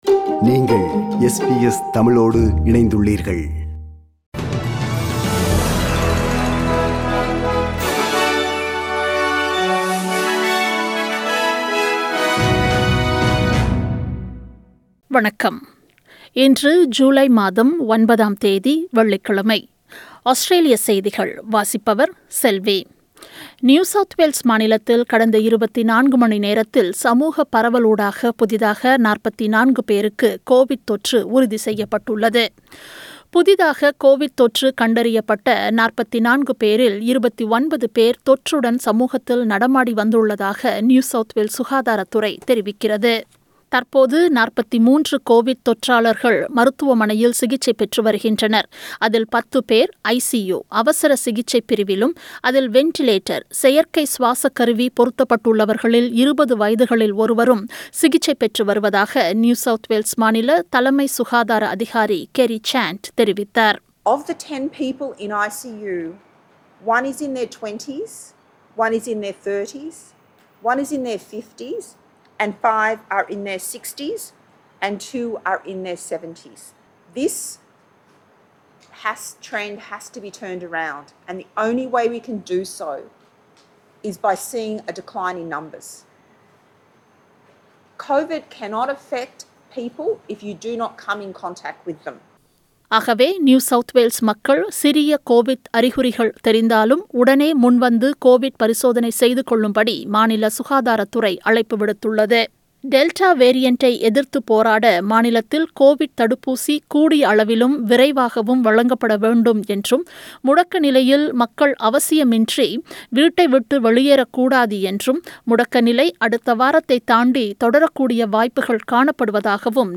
SBS தமிழ் ஒலிபரப்பின் இன்றைய (வெள்ளிக்கிழமை 09/07/2021) ஆஸ்திரேலியா குறித்த செய்திகள்.